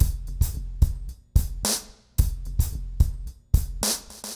RemixedDrums_110BPM_42.wav